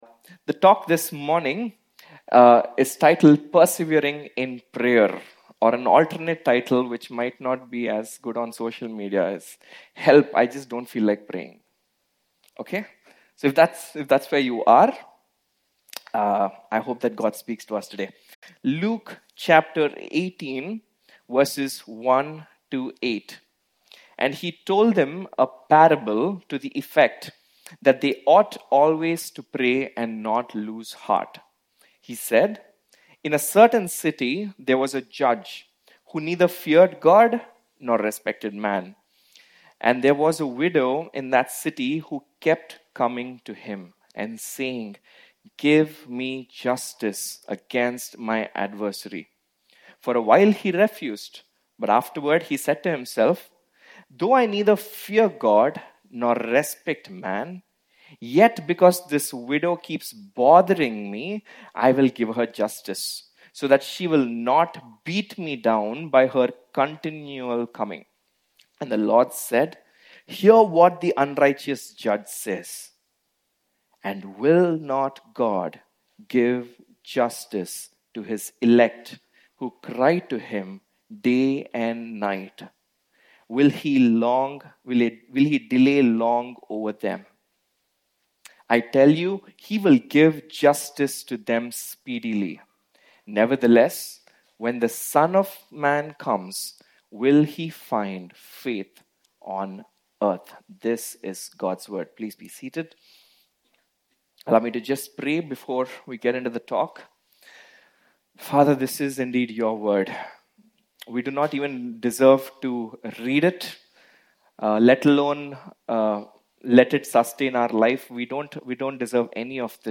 Sermon by Guest Speaker